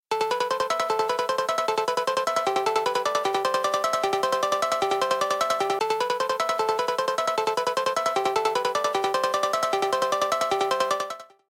• Качество: 128, Stereo
короткие
Классная мелодия смс на звонок вашего телефона